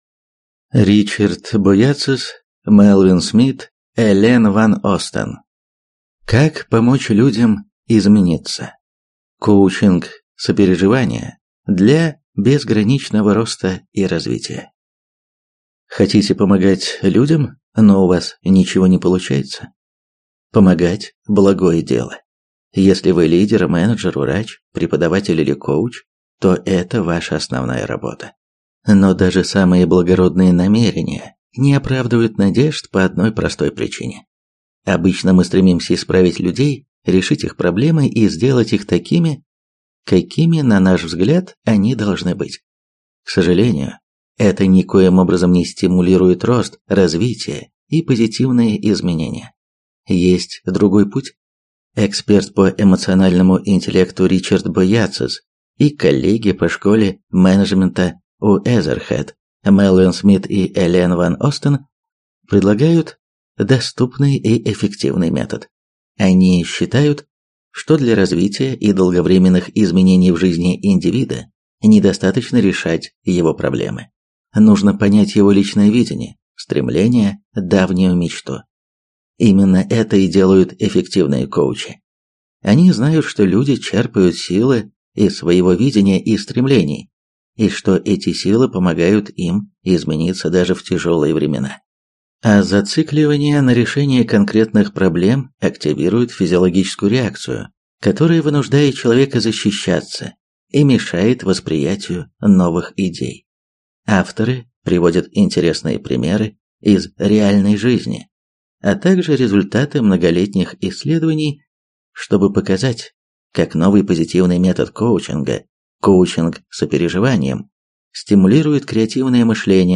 Аудиокнига Как помочь людям измениться | Библиотека аудиокниг